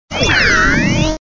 Fichier:Cri 0479 DP.ogg
contributions)Televersement cris 4G.